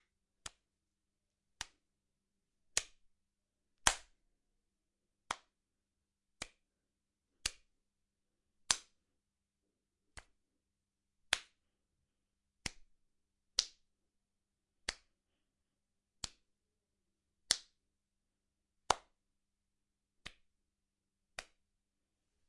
серия звуков с хлопками дай пять для монтажа high five